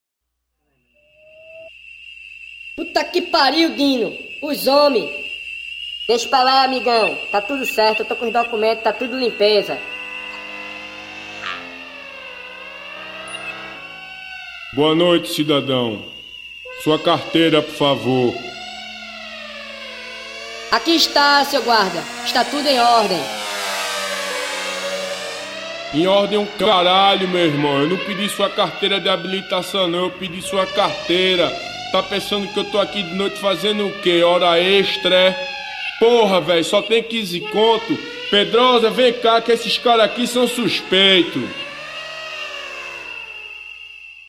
Baixo
Guitarra
Bateria
Som direto, sem excesso, com peso e intenção.